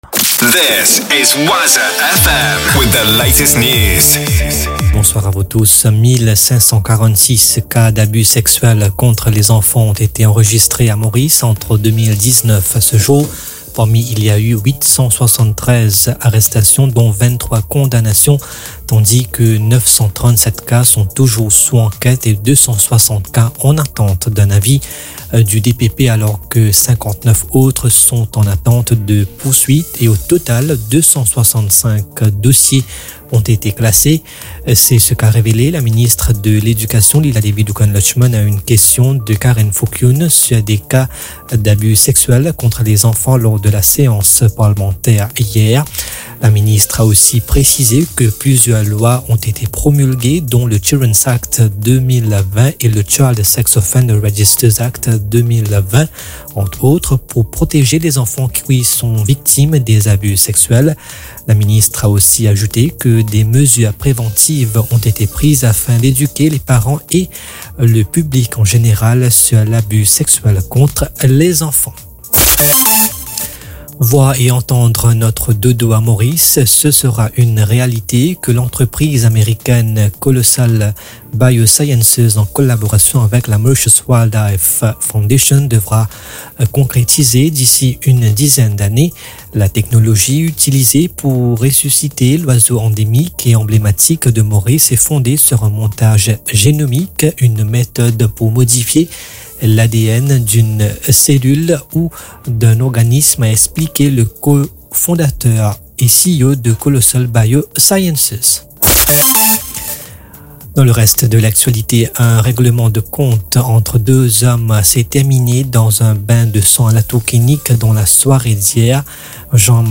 NEWS 20H - 22.11.24